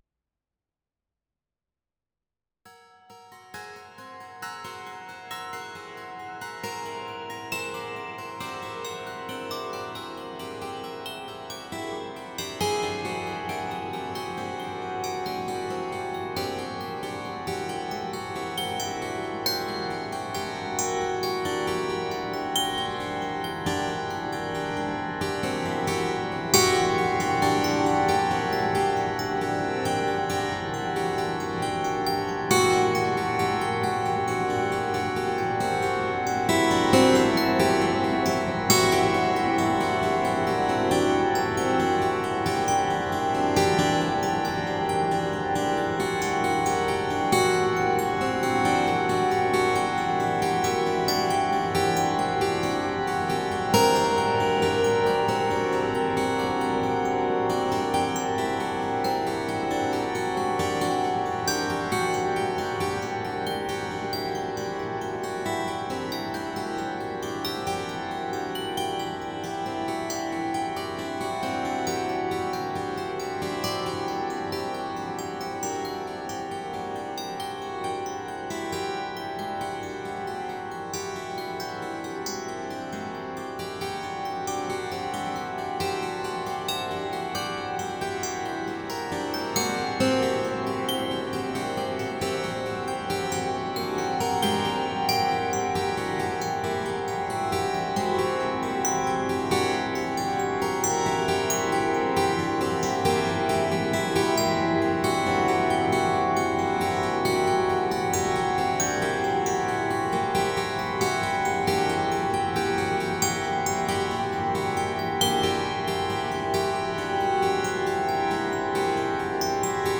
一聴して、何本ものアコースティックギターが、同時に即興的な分散和音オーケストレーションを行なっているように思えます。
また各々の音は空間を前後左右に、自由に飛び回ります。
ギター愛好家の方々にはもちろん、現代音楽、先端的テクノ、実験音楽をお好きな方々にもお薦めのアルバムです。